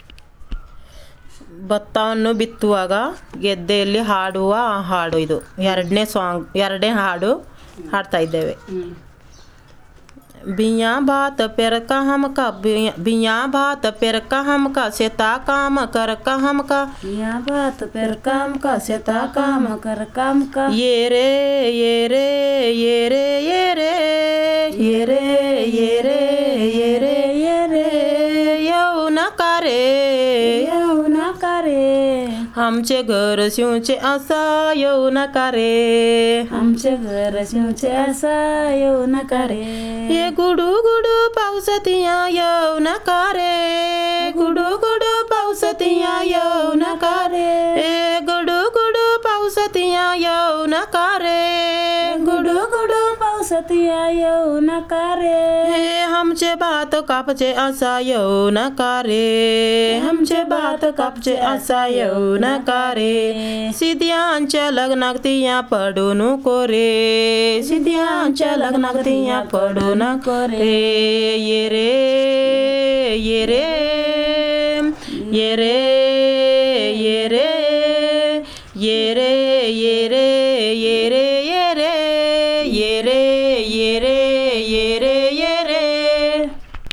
Performance of traditional folk song 'Biya bat peraka hamaka